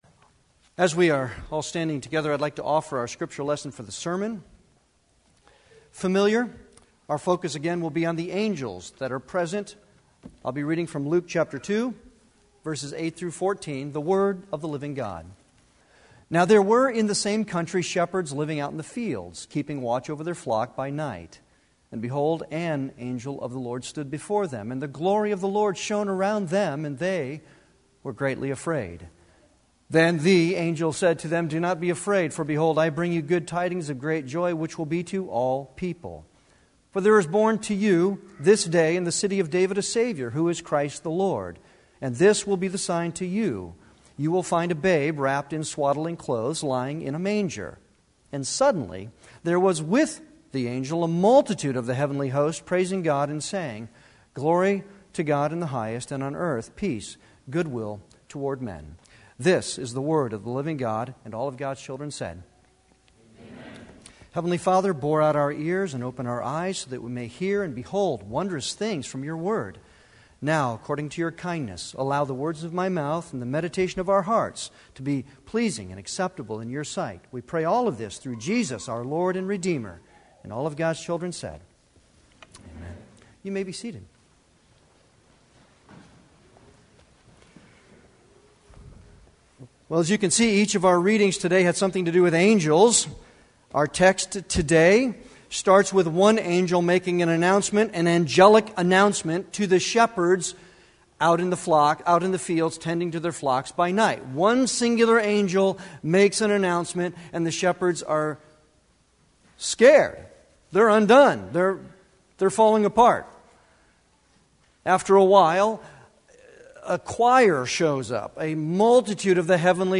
Series: Advent Sermons
Service Type: Sunday worship